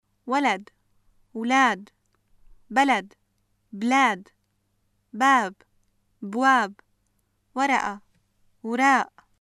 シリアのアラビア語 文法 名詞の複数形：例文
[walad (wlaad), balad (blaad), baab (bwaab), waraʔa (wraaʔ)]